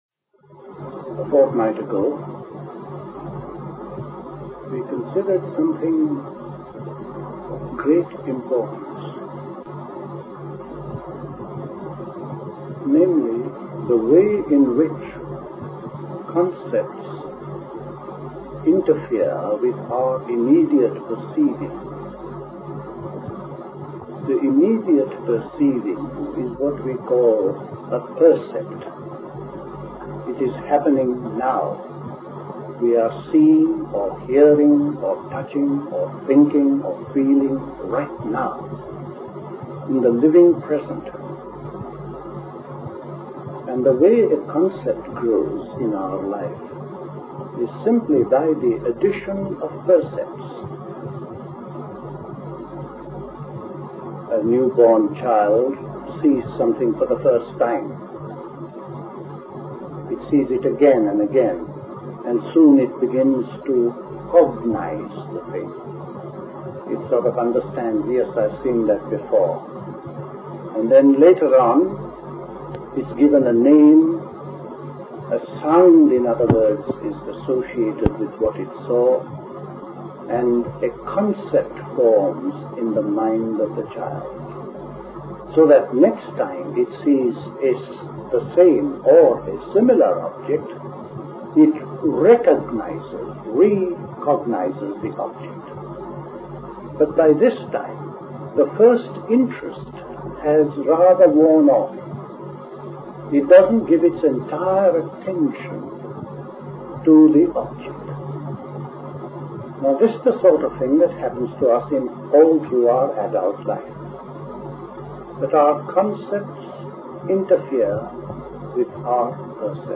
A talk
at Dilkusha, Forest Hill, London on 27th September 1969